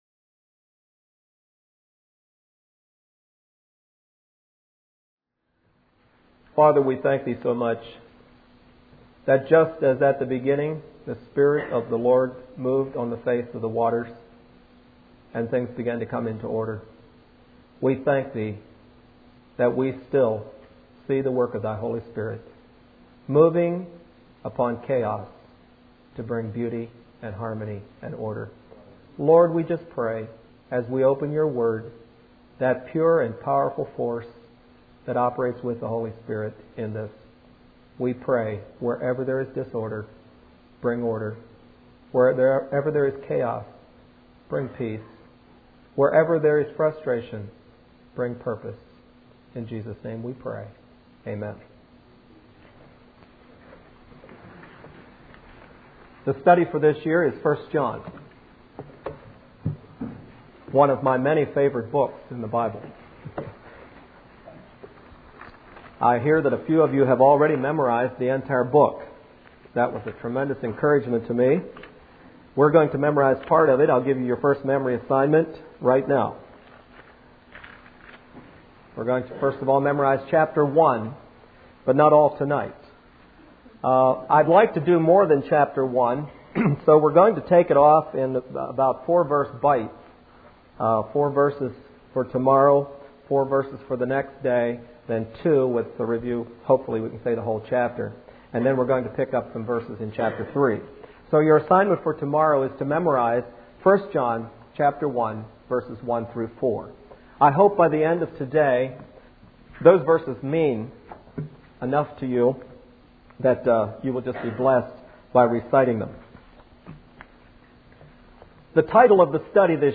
Facing The Facts Service Type: Midweek Meeting Speaker